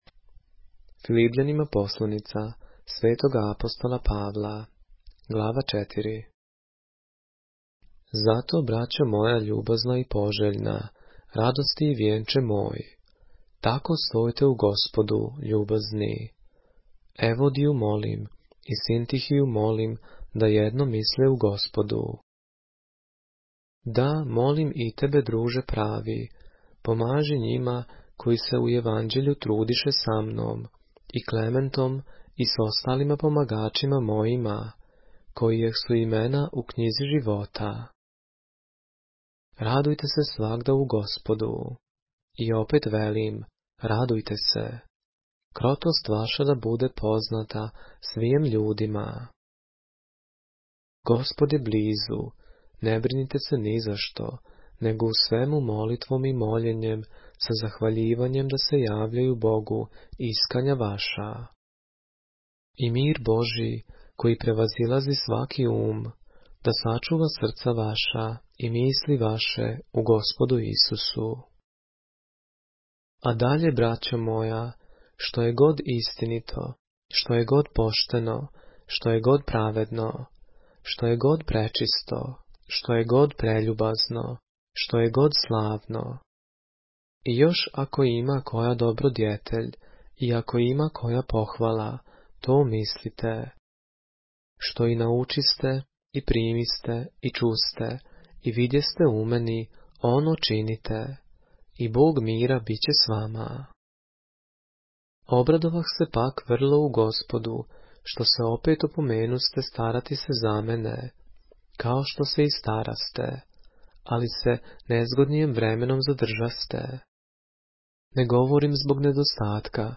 поглавље српске Библије - са аудио нарације - Philippians, chapter 4 of the Holy Bible in the Serbian language